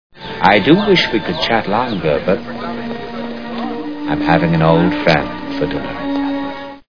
Silence of the Lambs Sound Bites